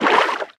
Sfx_creature_featherfish_swim_fast_05.ogg